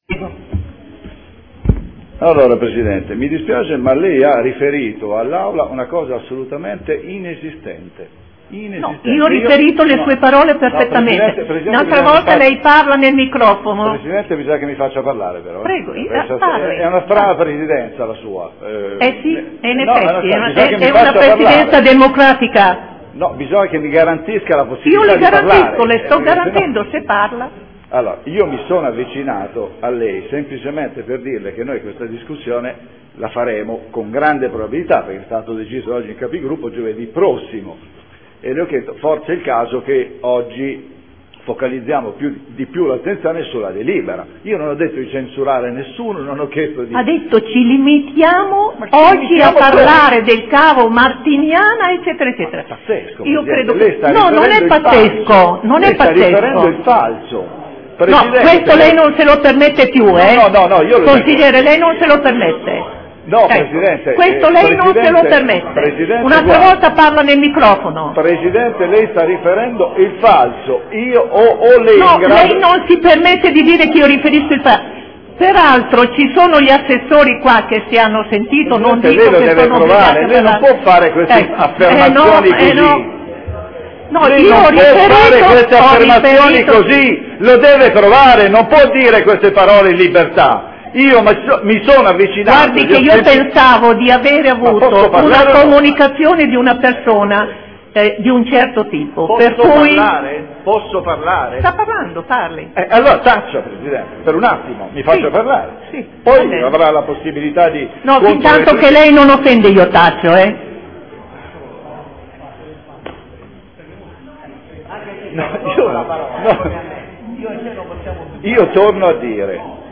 Seduta del 23/01/2014 per fatto personale